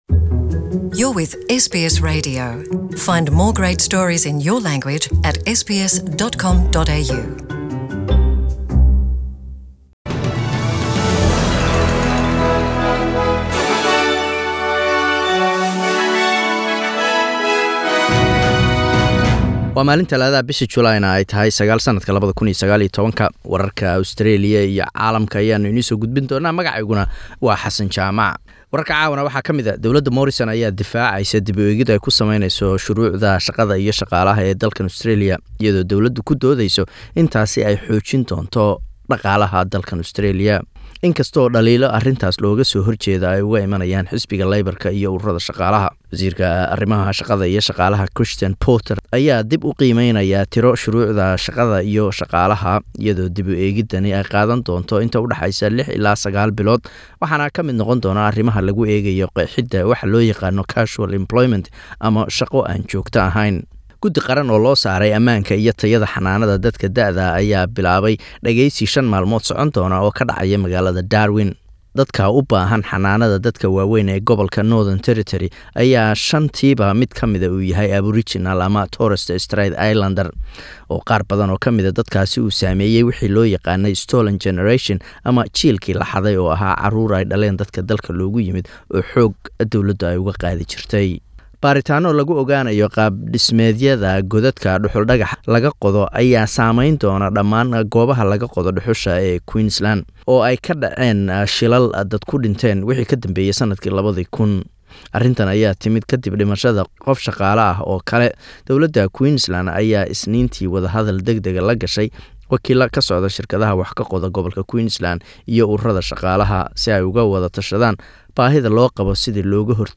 Today's News Bulletin